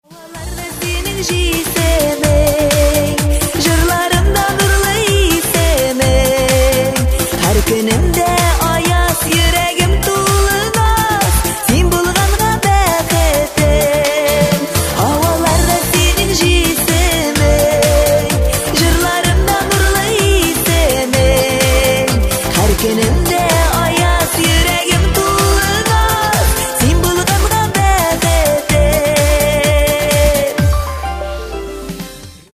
Kатегория: » Татарские рингтоны